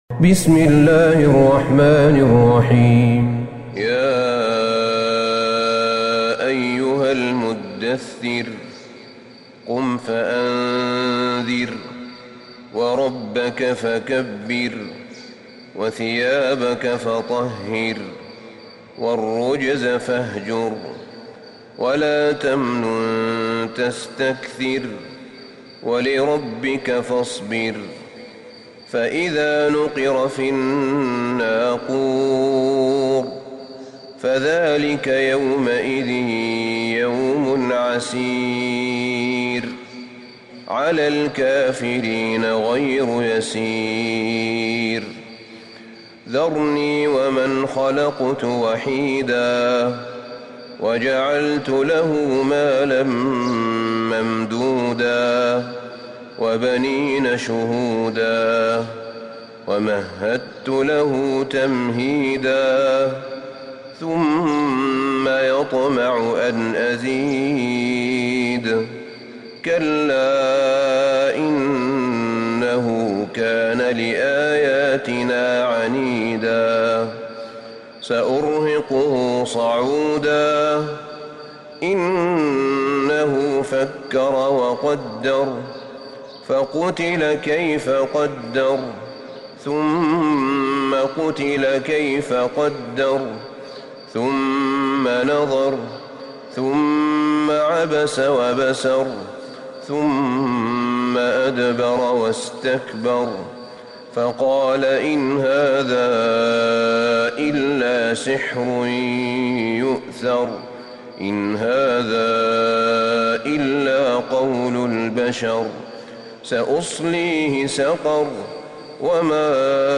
سورة المدثر Surat Al-Muddaththir > مصحف الشيخ أحمد بن طالب بن حميد من الحرم النبوي > المصحف - تلاوات الحرمين